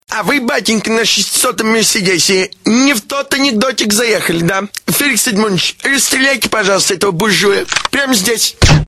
Категория: Реалтоны